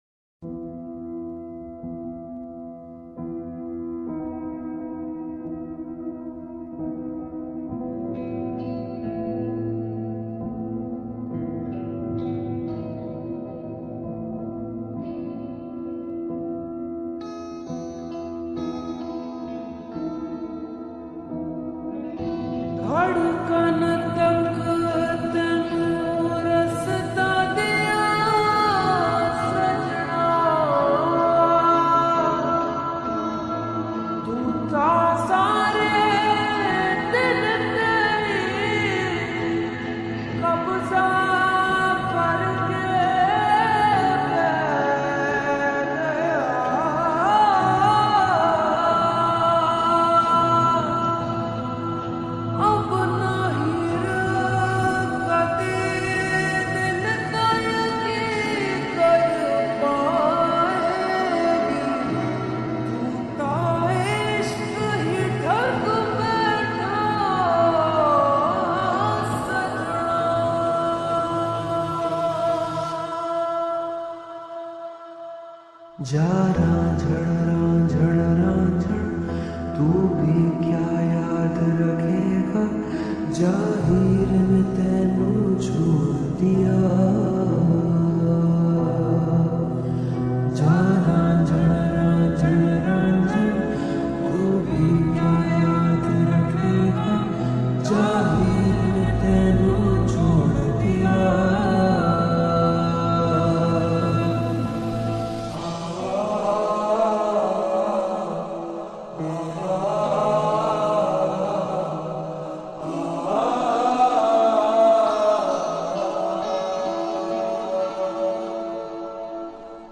SONG SLOWED